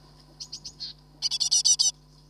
【おなかすいた】 [/DW/*n]
メスの営巣時の鳴き声/DW/(図-41)と巣立ち後のヒナの鳴き声(図-42)の「begging call（餌乞い声）」はよく似ている。